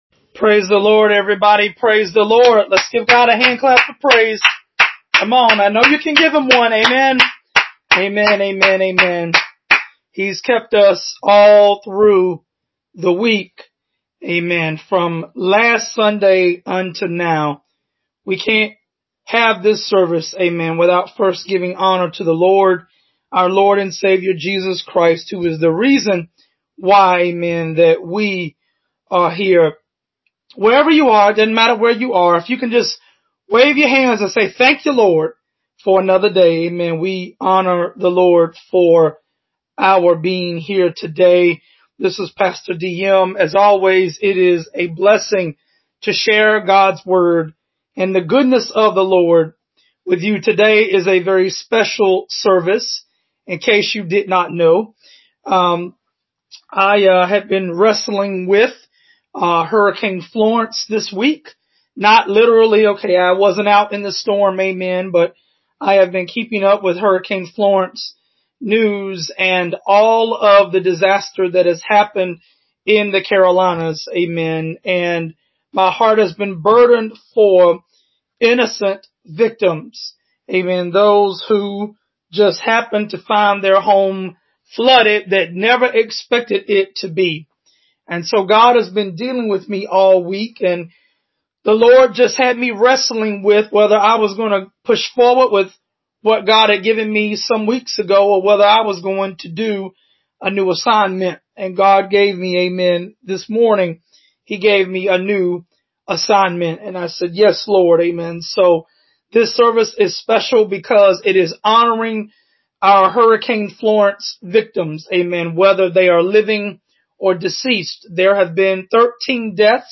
Order of Service
sermon-the-christian-declaration-of-divine-confidence-remembering-hurricane-florence-victims-psalm-461-3.mp3